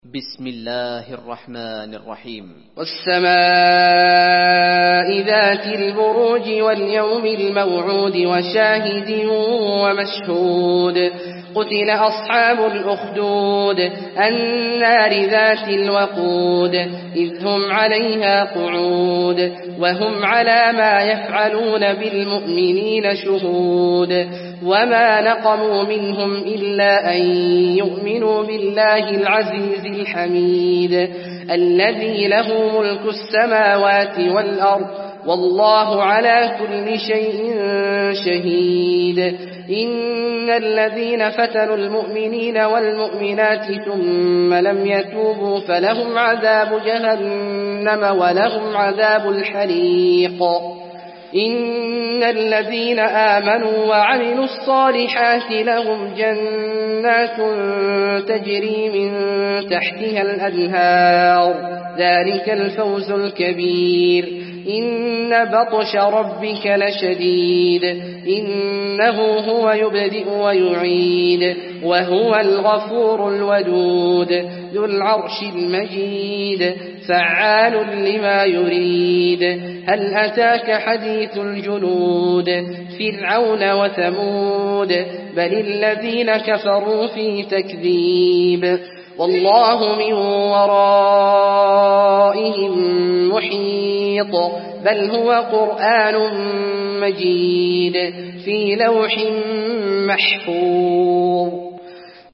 المكان: المسجد النبوي البروج